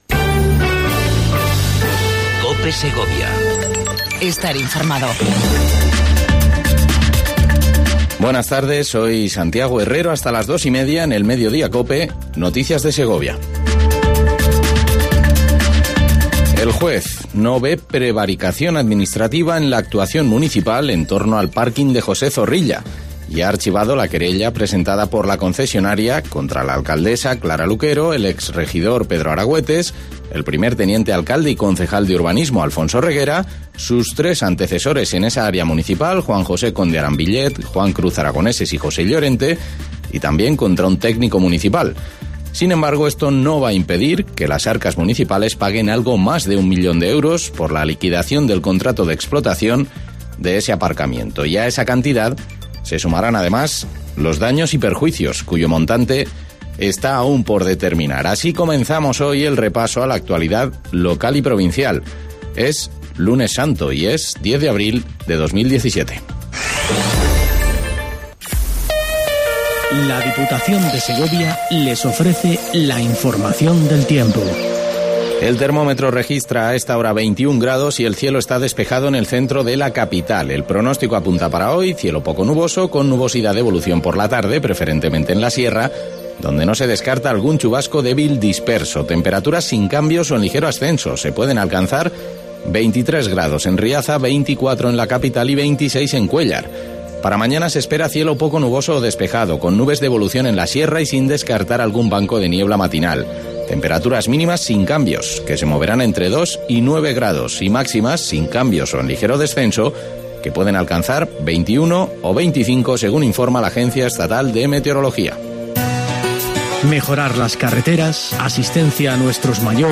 INFORMATIVO MEDIODIA COPE EN SEGOVIA 10 04 17